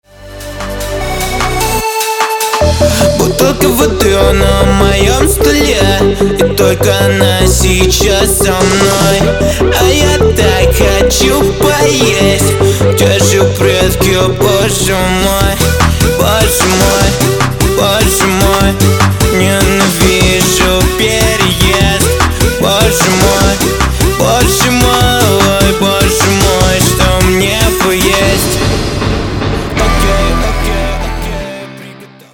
Муж, Закадровый текст
Звуковая карта focusrite solo 3rd, микрофон se electronics x1 s